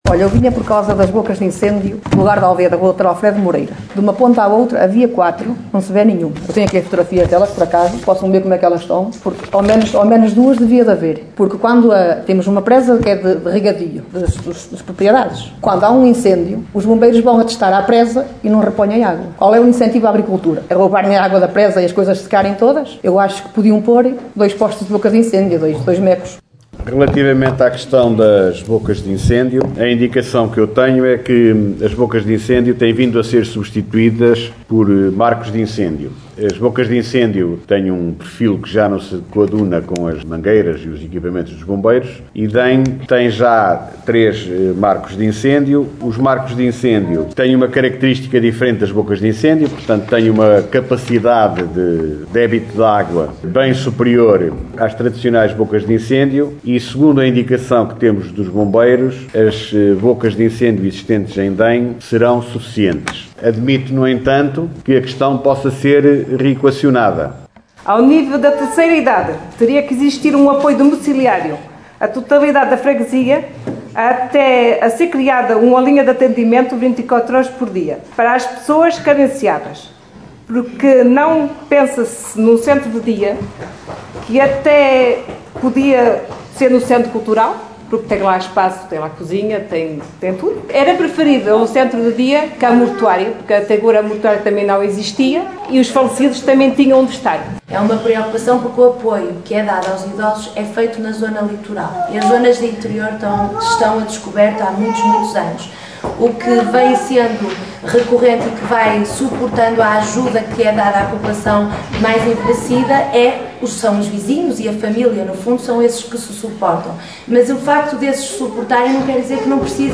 Os habitantes locais fizeram as perguntas, os vereadores foram respondendo na reunião que decorreu ontem ao final da tarde.